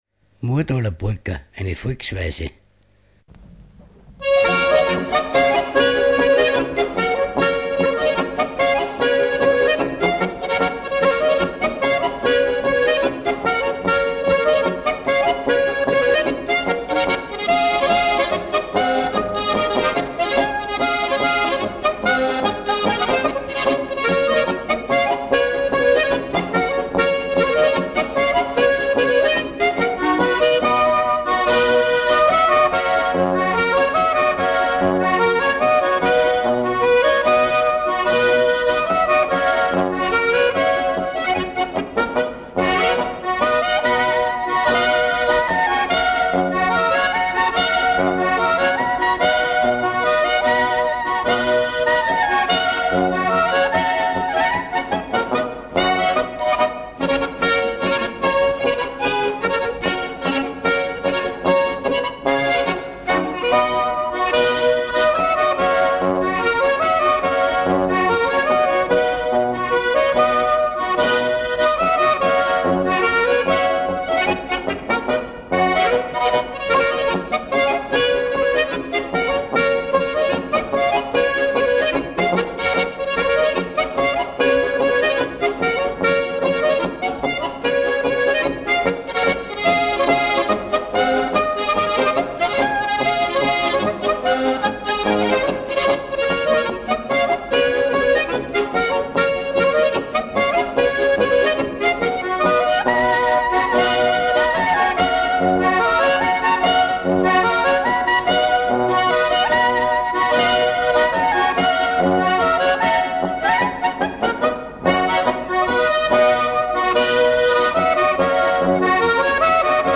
Multipart Music, Instrumentation of Sound, Instrumentalization of Sound, Sound and Society, Performance as Instrumentation, Tradition, Revival
Folk & traditional music